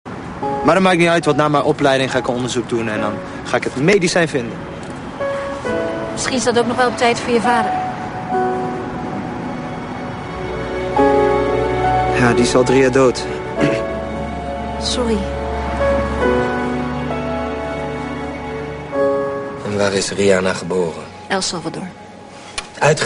Please ID this piece of piano music, it's IN the background.
What's the name of the piano background music???